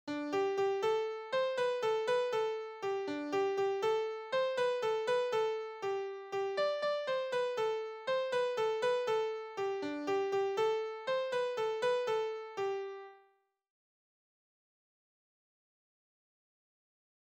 Sables-d'Olonne (Les)
Genre strophique